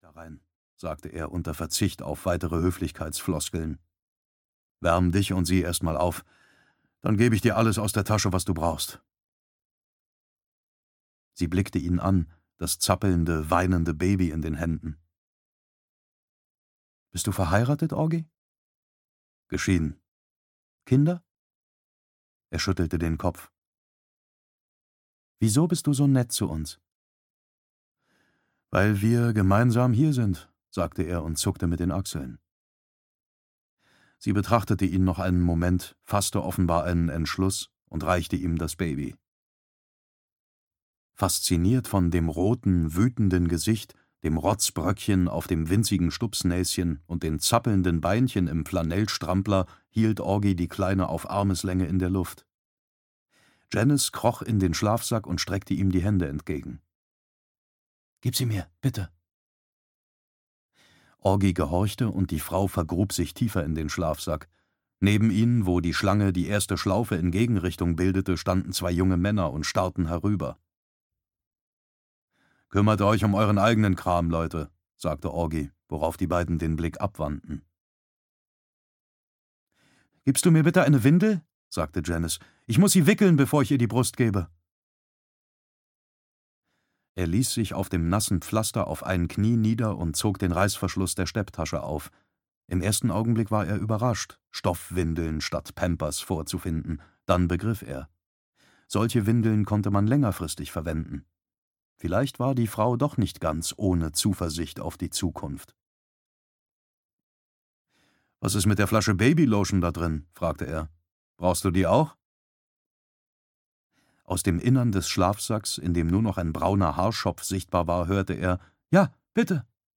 Mr. Mercedes (DE) audiokniha
Ukázka z knihy
• InterpretDavid Nathan